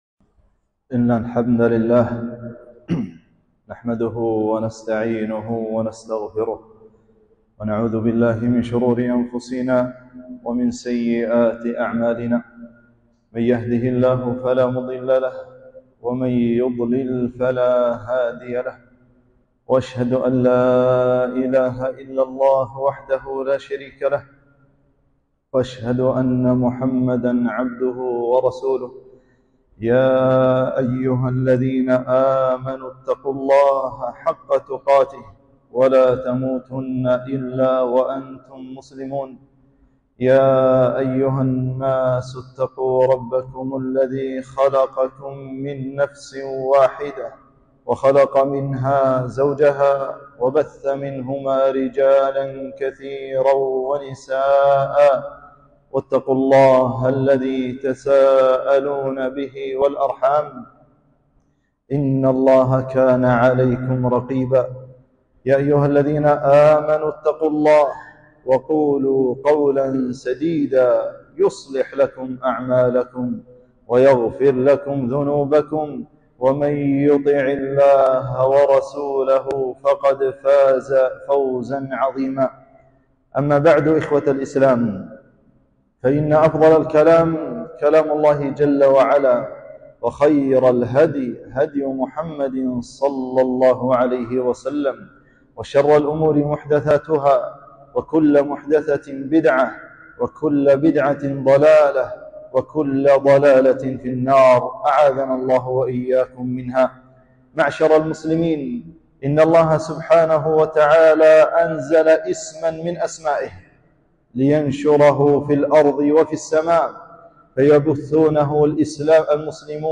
خطبة - فضل تحية الإسلام "السلام عليكم"